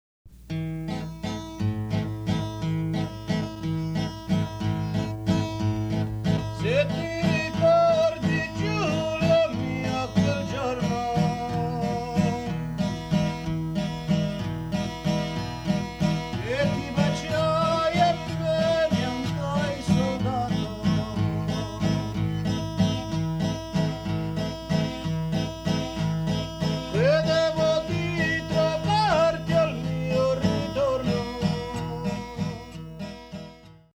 In its organization perhaps one of the most complex forms of choral singing in Italy, the Lassa Piglia strikingly resembles the Eastern Orthodox hymnodic style, unaltered since Byzantine times. Three leaders alternate within a single strophe, and with the chorus elaborate what is basically a couplet in such a way that the text is gradually broken down from a complex line of poetry, which is sung in two or three part harmony.